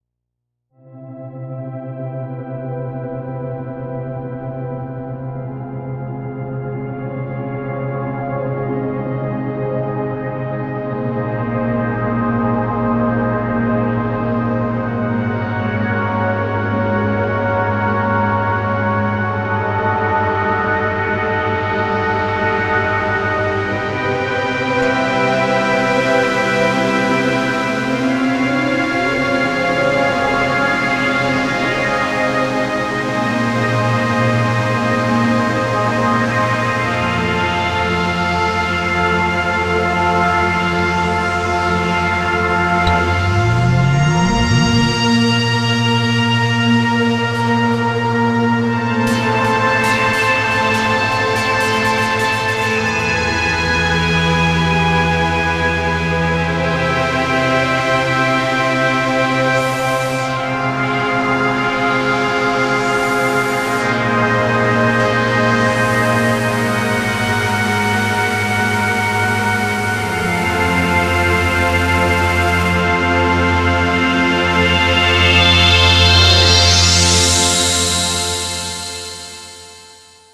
Atmospheric exploration music.